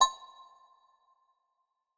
PERC - SMOKE BREAK.wav